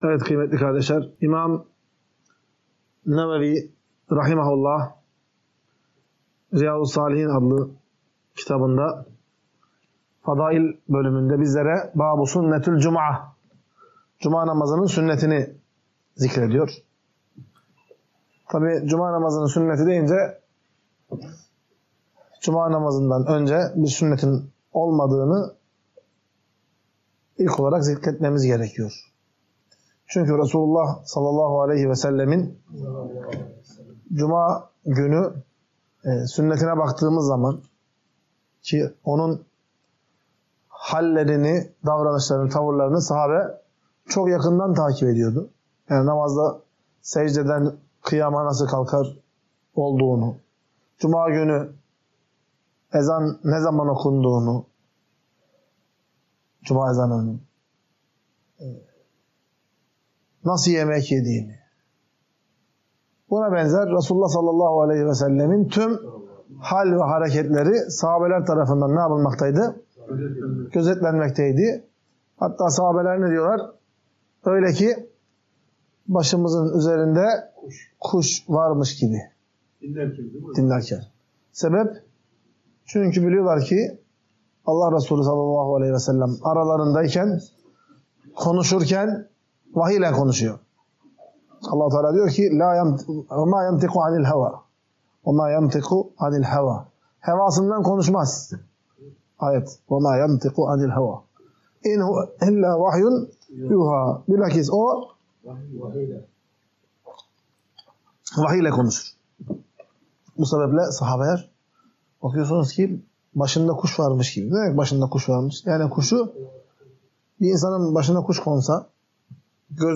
Ders - 24.